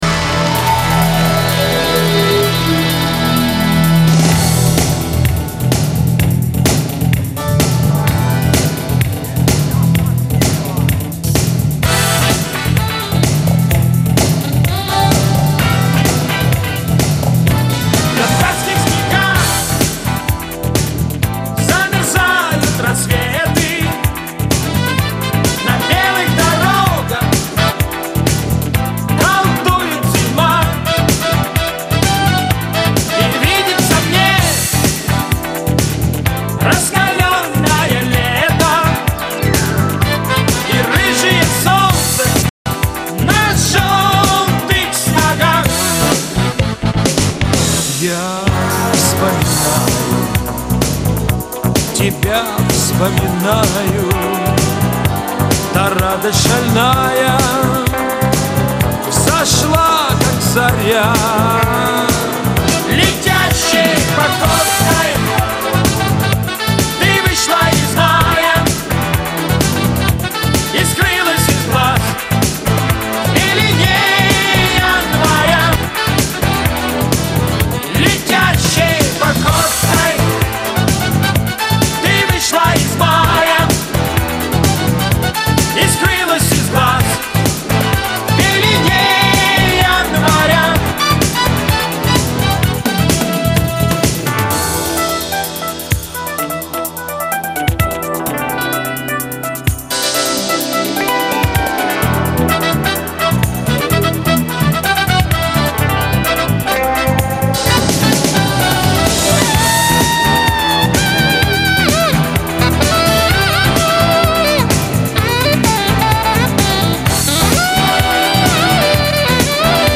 кавер версия песни